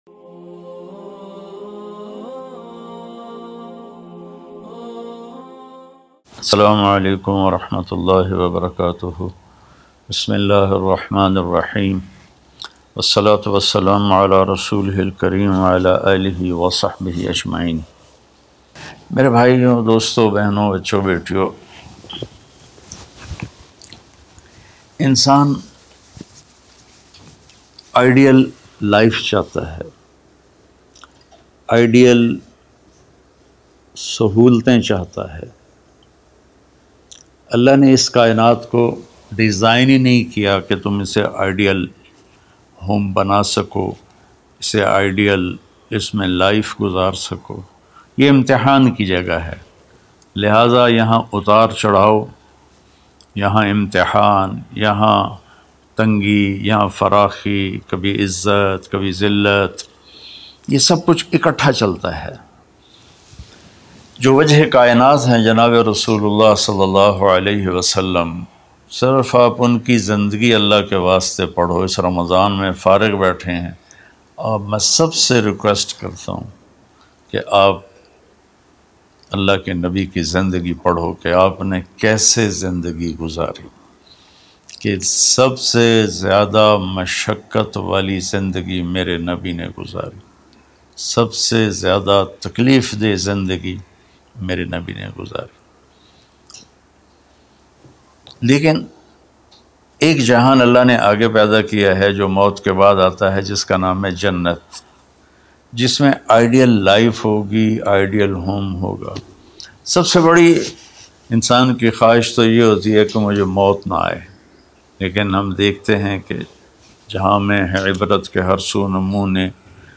Ramzan Kaisy Guzarin Molana Tariq Jamil Latest Bayan MP3 Download